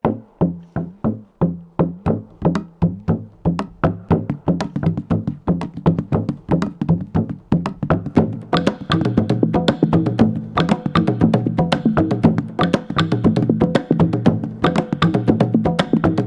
Language Perc Loop.wav